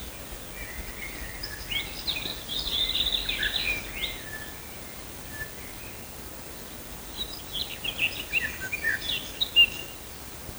Birds -> Warblers ->
Garden Warbler, Sylvia borin
StatusSinging male in breeding season